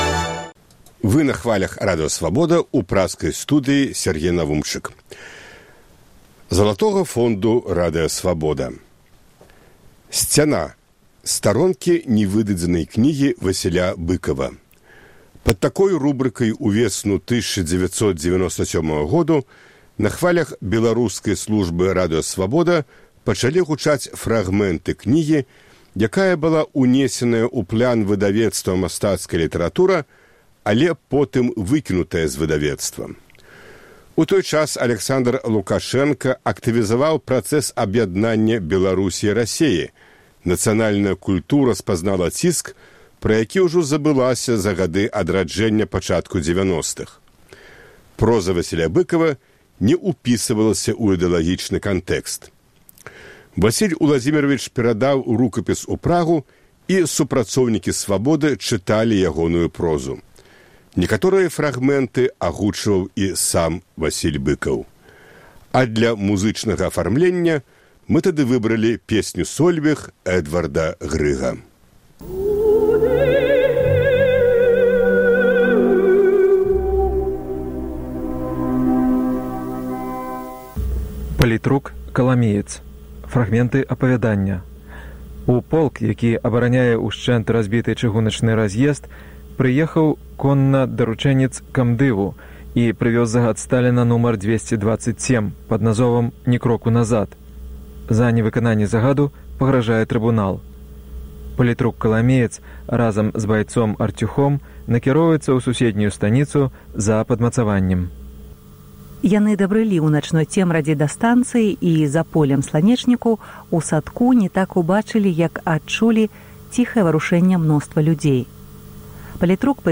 Васіль Уладзімеравіч перадаў рукапіс ў Прагу, і супрацоўнікі Свабоды чыталі ягоную прозу.